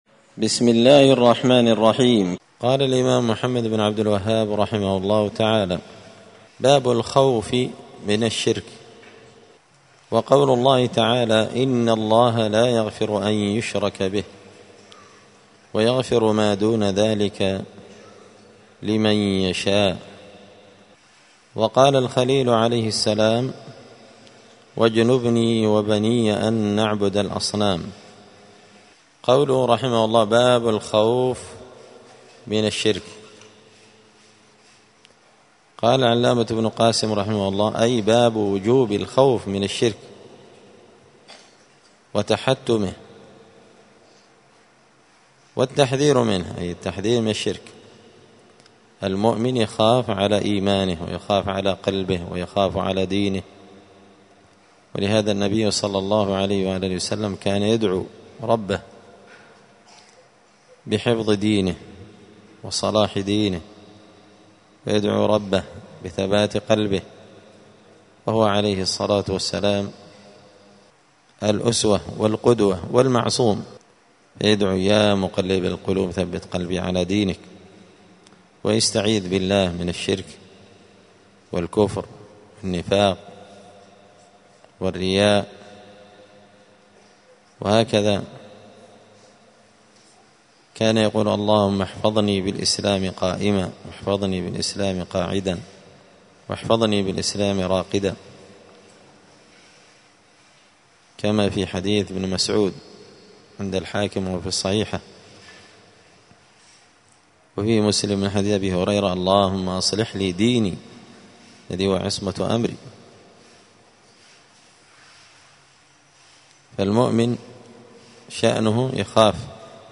دار الحديث السلفية بمسجد الفرقان بقشن المهرة اليمن
*الدرس السادس عشر (16) {الباب الرابع باب الخوف من الشرك…}*